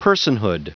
Prononciation du mot personhood en anglais (fichier audio)
Prononciation du mot : personhood